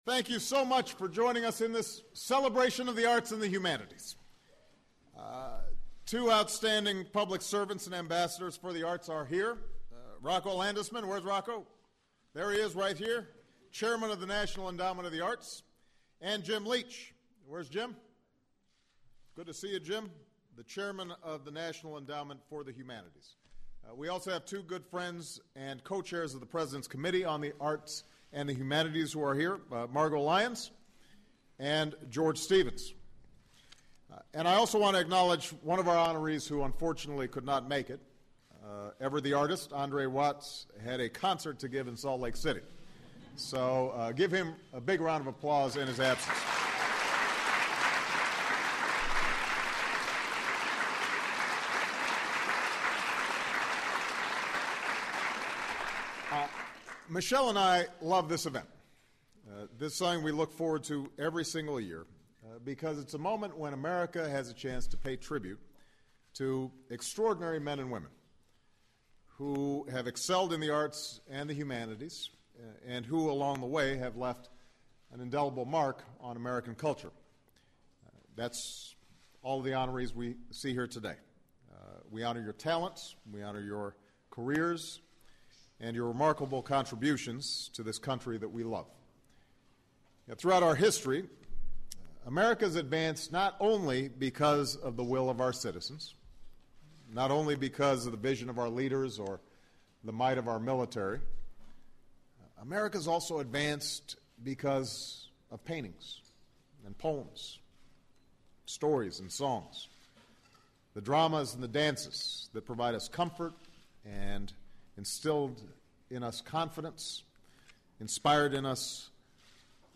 U.S. President Barack Obama speaks at the 2011 National Medal of Arts and National Humanities Medal presentation ceremony